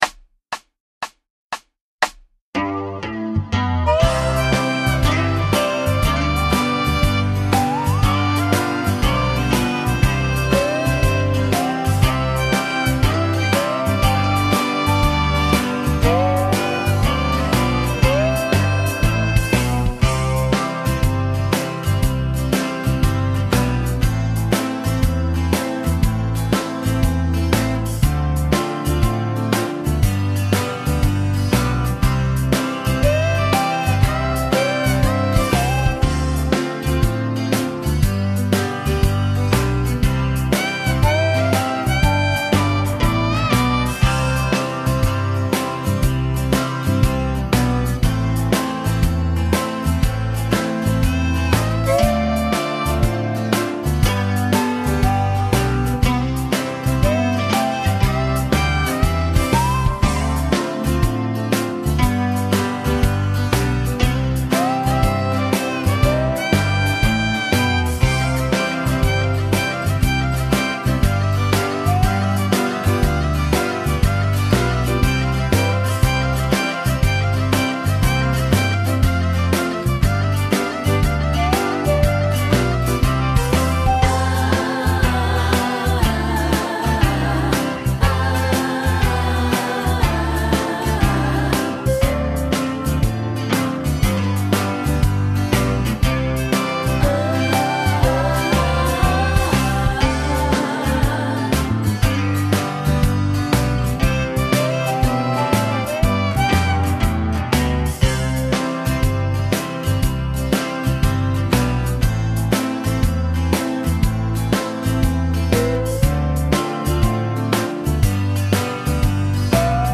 Genere: Moderato
Scarica la Base Mp3 (3,72 MB)